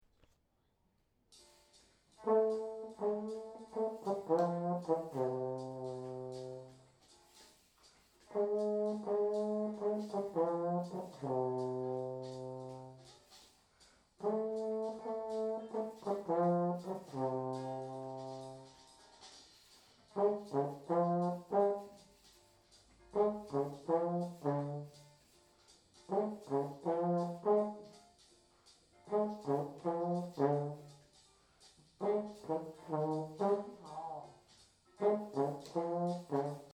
Prête moi tes yeux : trombone